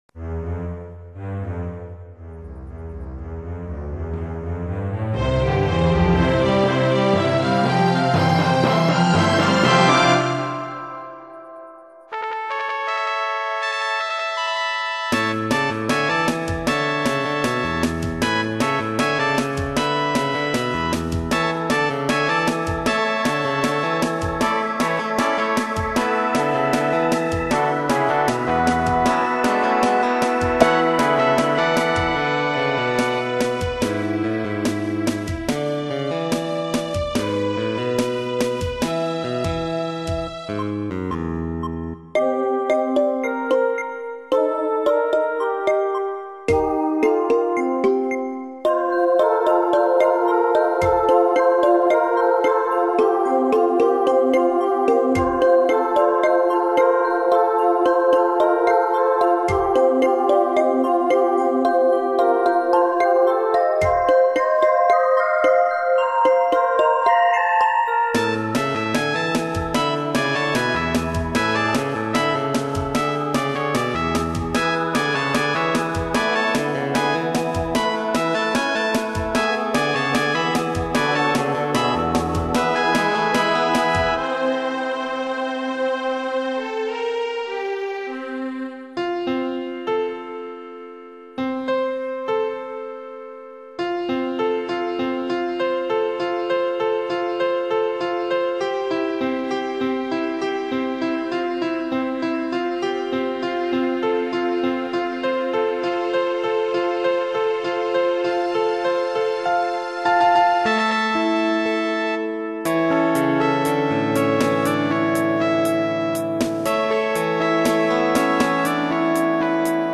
Christmas spirit. This is an instrumental written for a family Christmas video.
It darts this way and that, keeping up with pictures that you won't see just playing the file.